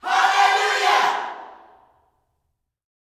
ALLELUJAH6.wav